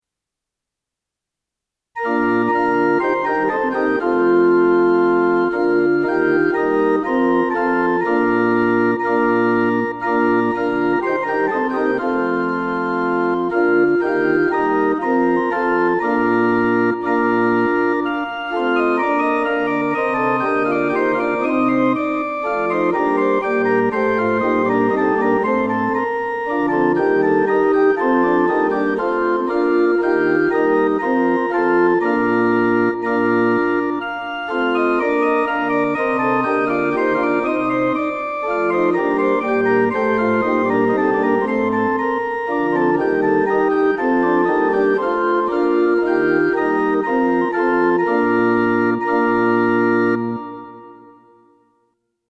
Ding Dong Merrily – Backing | Ipswich Hospital Community Choir
Ding-Dong-Merrily-Backing.mp3